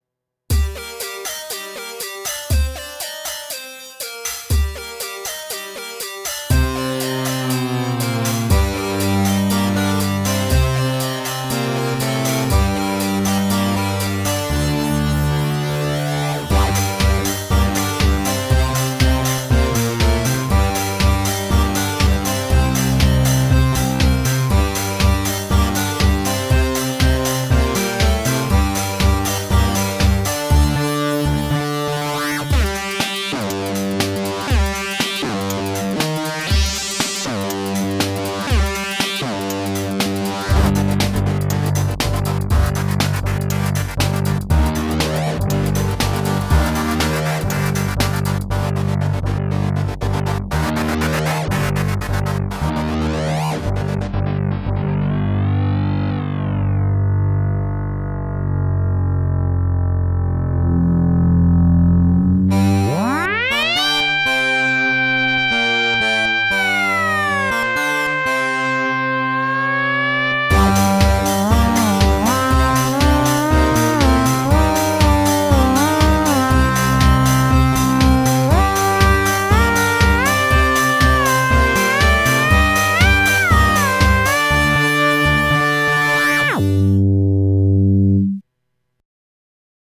Here's mine, only used EQ, Compressors and PAN, so that we really hear the Preen.
The drums come from Drumaxx VST.
;D  It has quite an 'Eastern' sound to it.